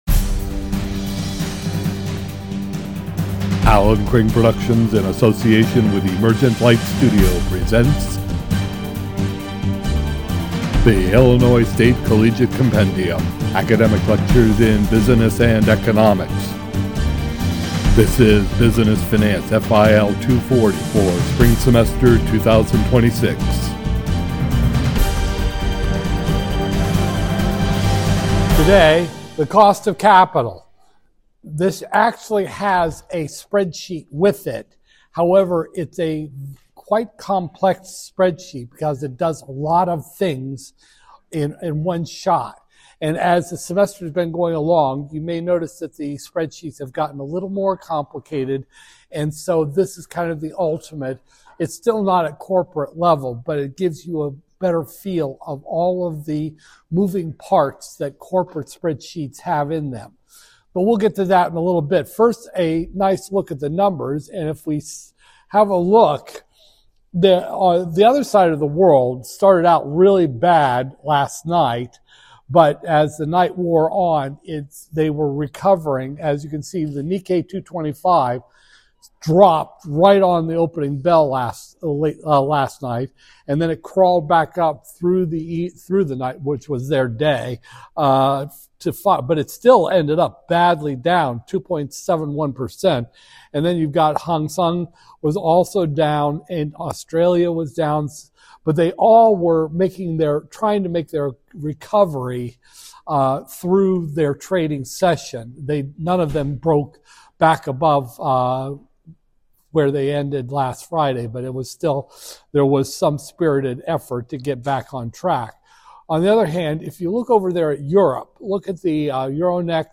Business Finance, FIL 240-002, Spring 2026, Lecture 19